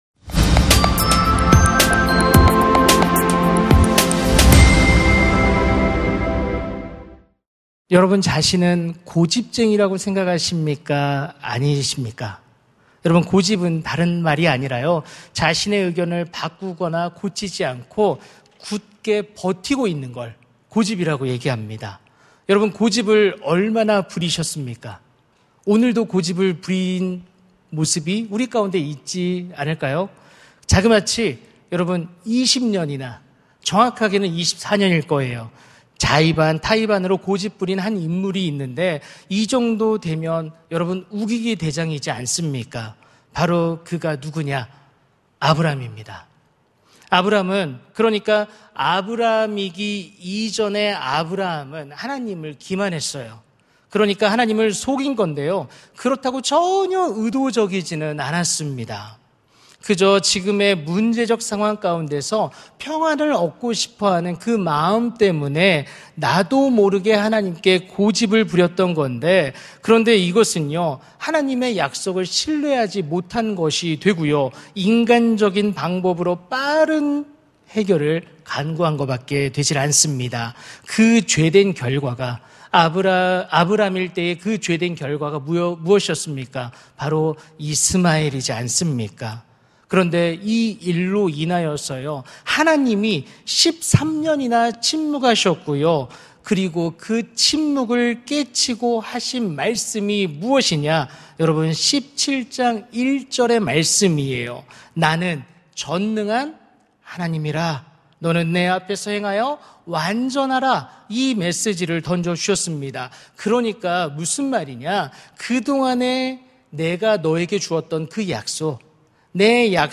설교 : 금요심야기도회 (분당채플) 아니라 말씀하시는 하나님의 YES! 설교본문 : 창세기 17:15-22